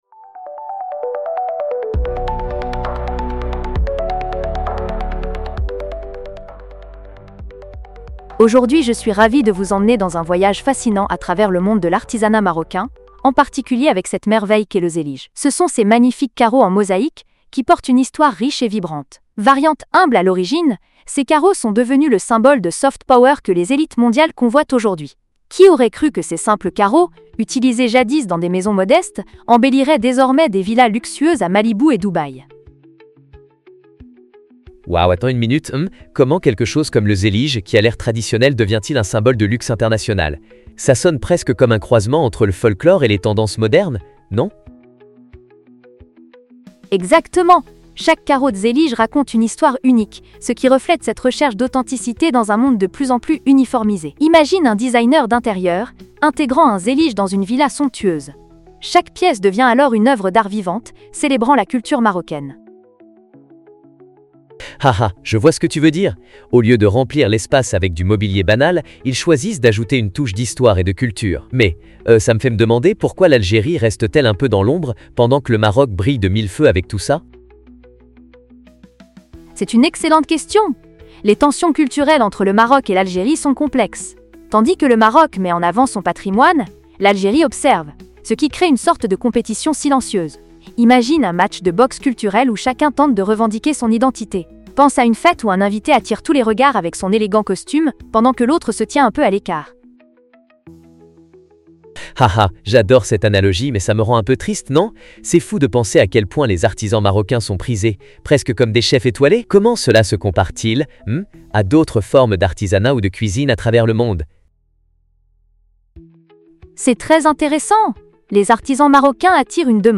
Débat en podcast (3.08 Mo) On croyait que seules les mégapoles pouvaient faire la pluie et le beau temps en matière de tendances.